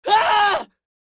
Screams Male 01
Screams Male 01.wav